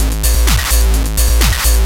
DS 128-BPM A2.wav